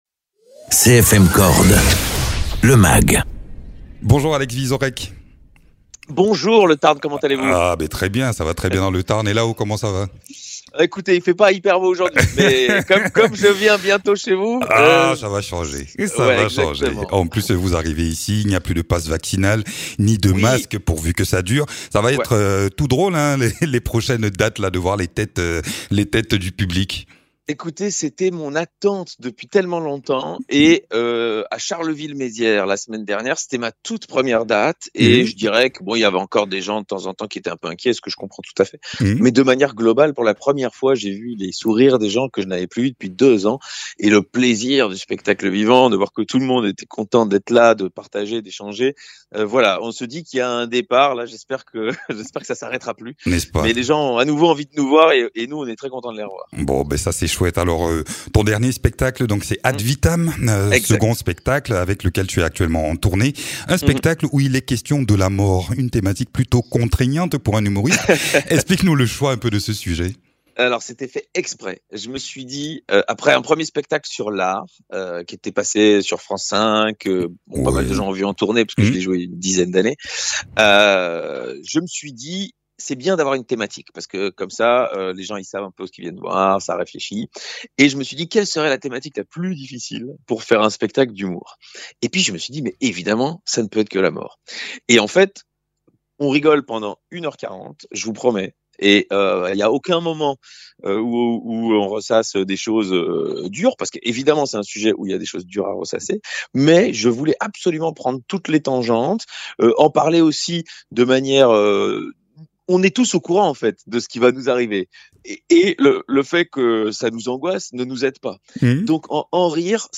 Interviews
Invité(s) : Alex Vizorek, comédien-humoriste et chroniqueur.